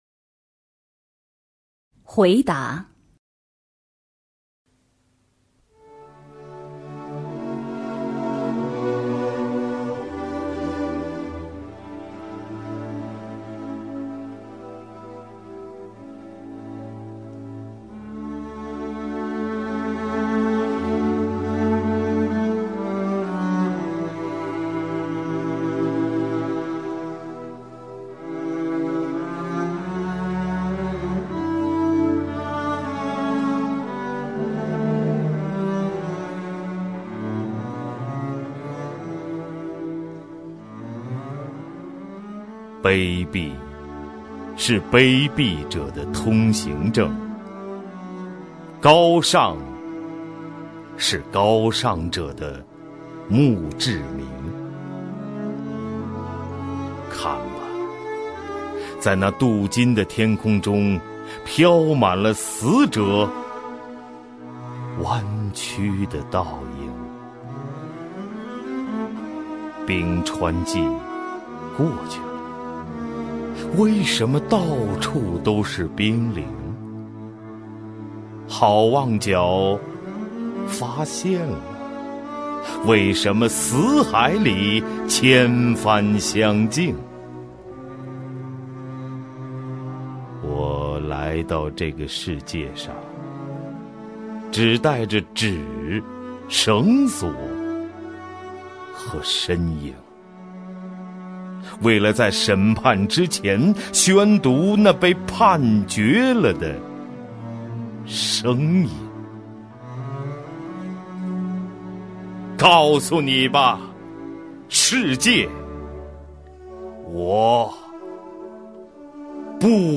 首页 视听 名家朗诵欣赏 赵屹鸥
赵屹鸥朗诵：《回答》(北岛)
HuiDa_BeiDao(ZhaoYiOu).mp3